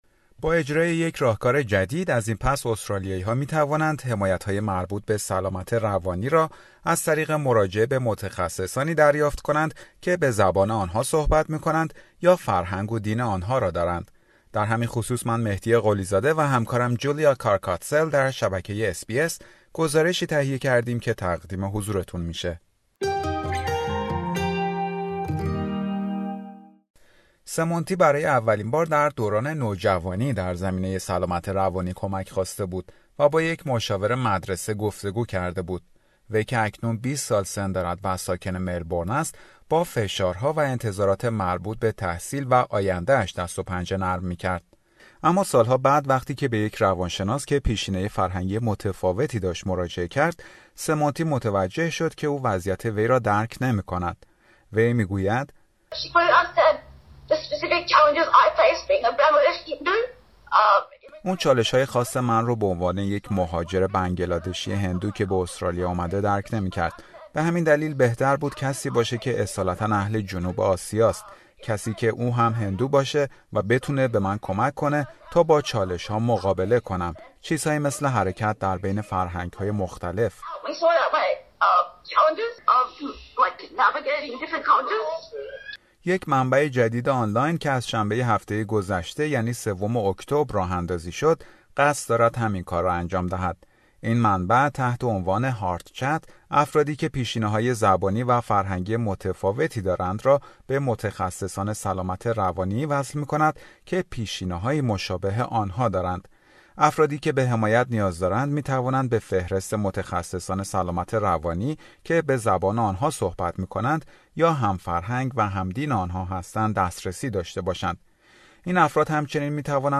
گفتگو با یک پزشک و روان درمانگر در مورد نحوه دسترسی به حمایت های رایگان در زمینه سلامت روانی در استرالیا